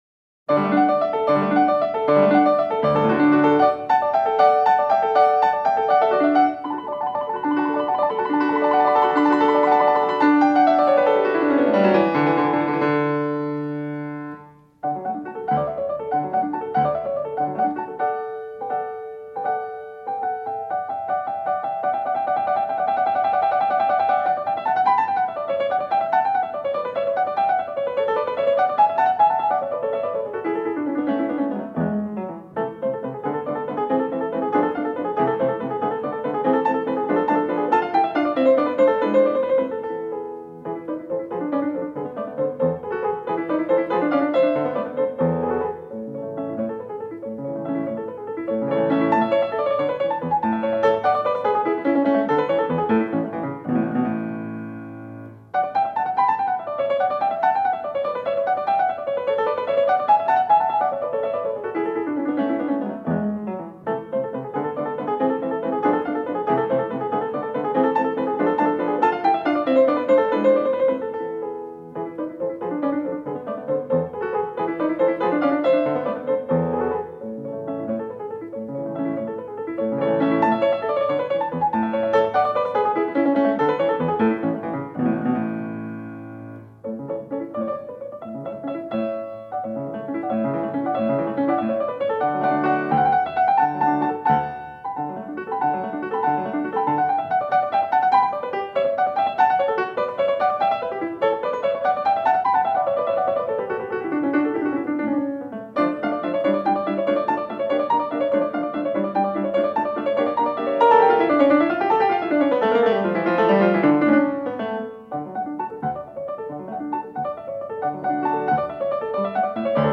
Сонаты для фортепиано.